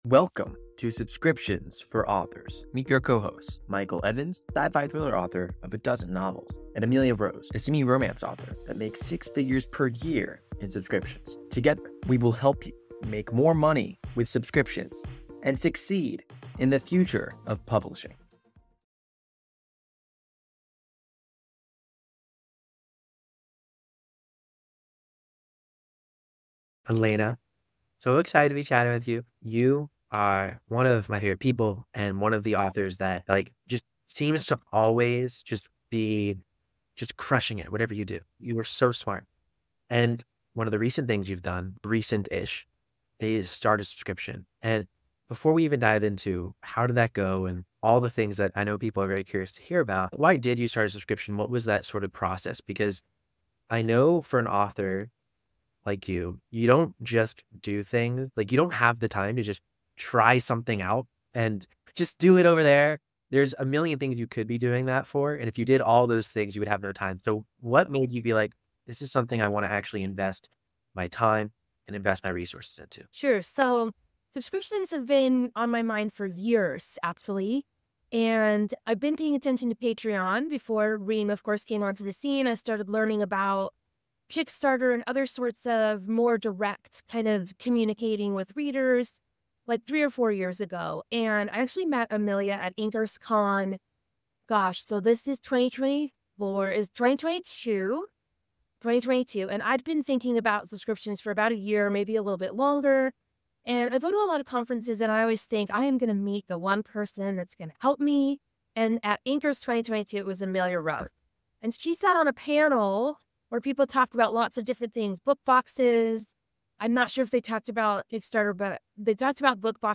for a discussion on the essentials of author success!